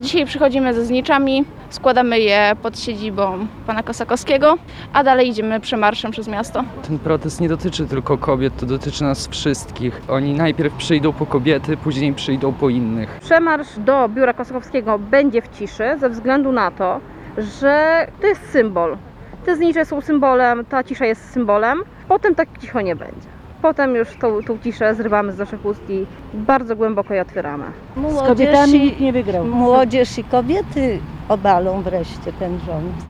Pod pomnikiem Rusałki przy ulicy Wojska Polskiego zgromadziło się kilkadziesiąt osób ze zniczami w dłoniach.
„Te znicze są symbolem” – usłyszeli od protestujących reporterzy Radia 5.
Protestujacy-ok-clip-3.mp3